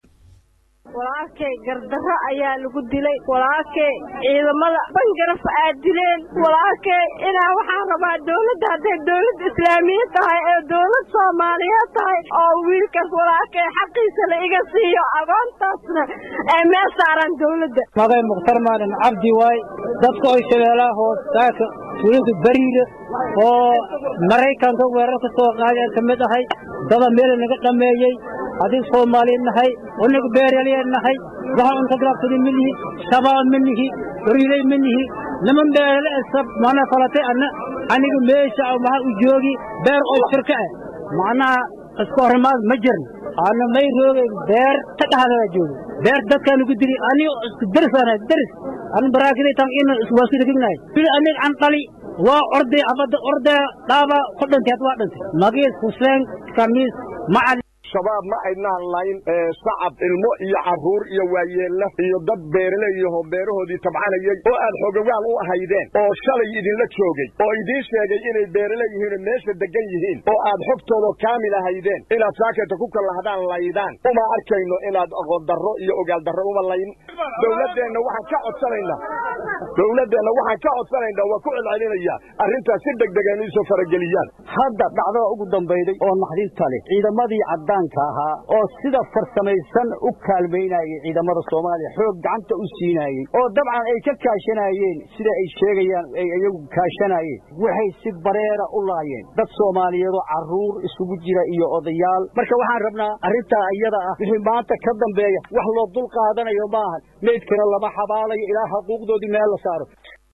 Dhageyso Codka: Ehelada dadkii lagu dilay Bariire oo Fariin u diray Dowladda Soomaaliya | Goobsan Media Inc